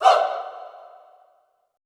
Index of /90_sSampleCDs/Best Service - Extended Classical Choir/Partition I/FEM SHOUTS
FEM HOO   -R.wav